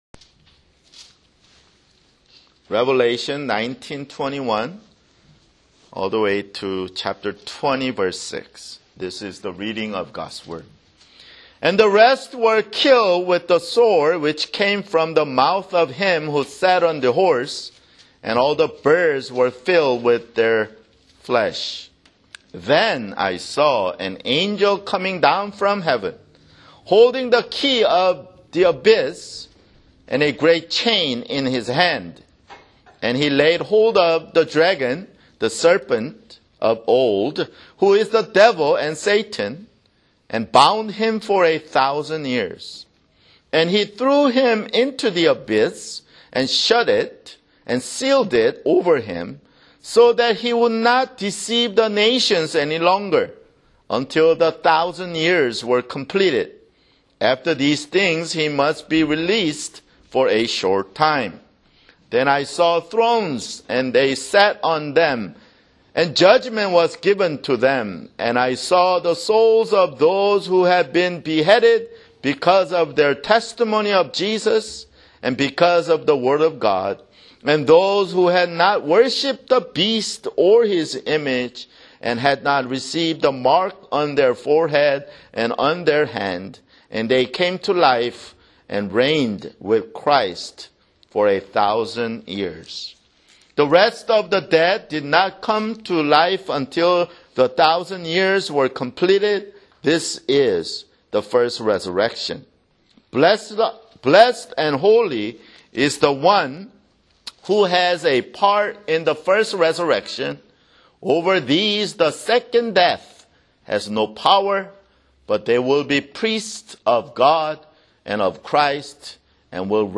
[Sermon] Revelation (76)